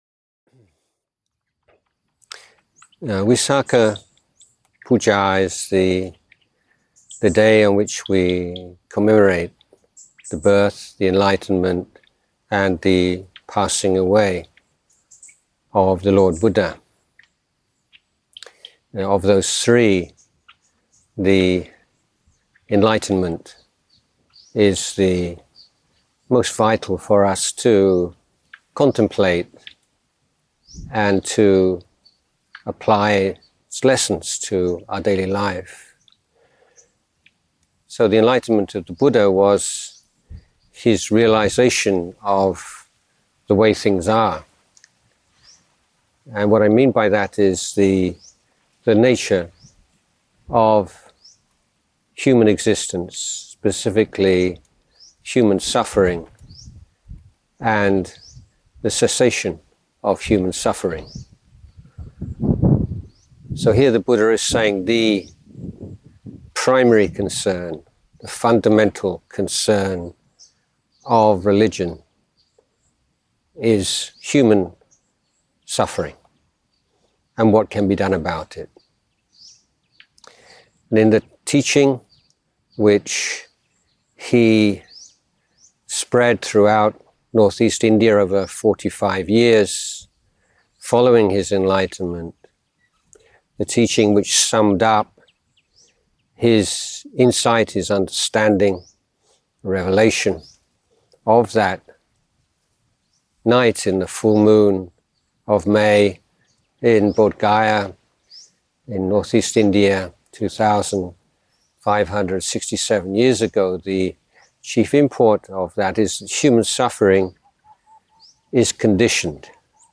Dhamma Teachings for Visakha Puja